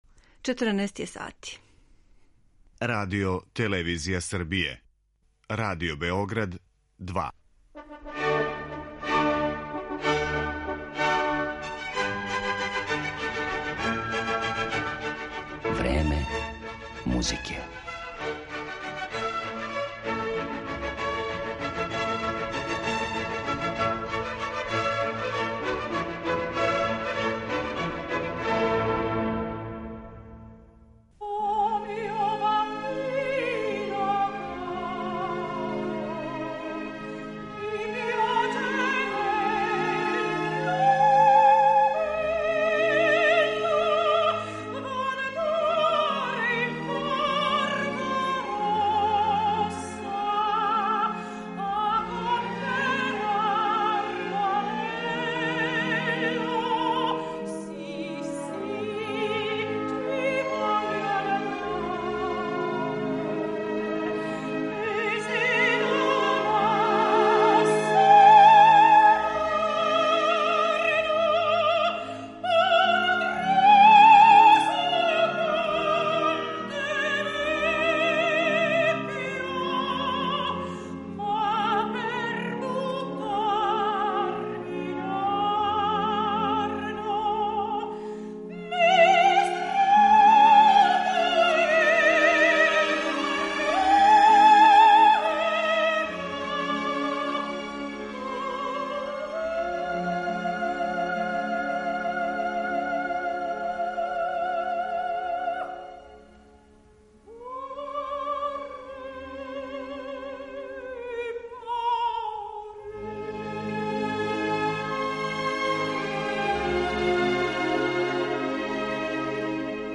Иако су неке од њених најранијих улога биле и Елза у „Лоенгрину" и Ева у „Мајсторима певачима", сопран ове уметнице идеално је одговарао ликовима из Пучинијевих и Вердијевих опера, попут Тоске, Мими, Виолете или Дездемоне.